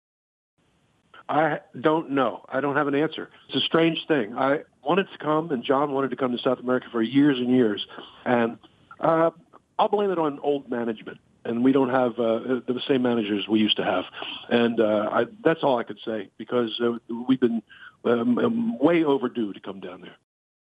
Entrevistamos a Daryl Hall, días antes de su presentación en nuestro país, para adelantar lo que se vivirá la noche del 8 de junio en el Movistar Arena.